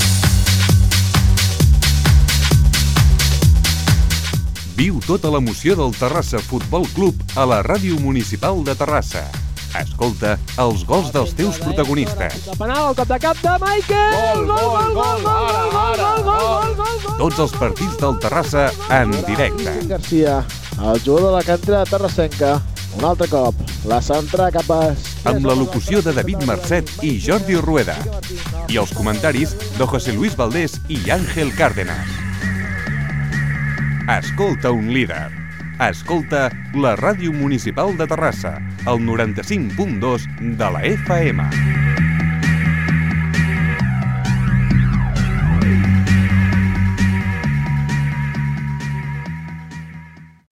Promoció de les transmissions de futbol masculí del Terrassa